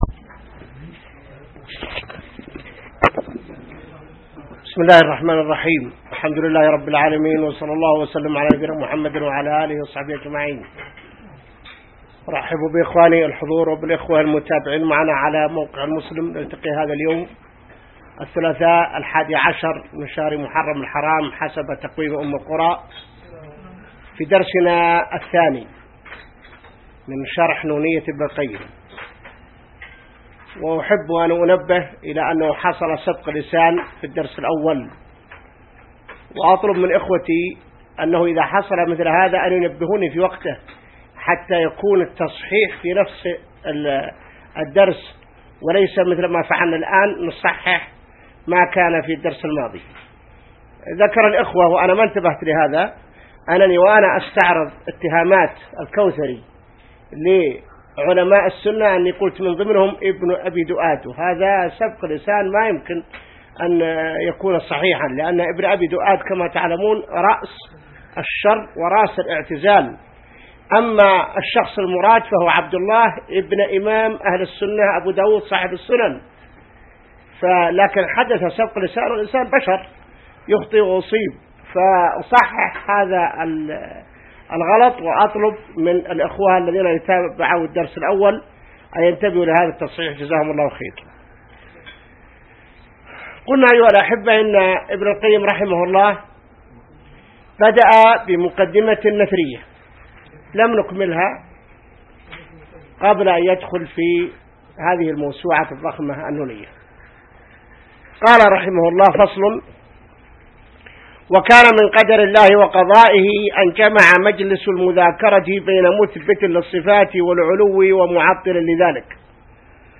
الدرس الثاني شرح نونية ابن القيم | موقع المسلم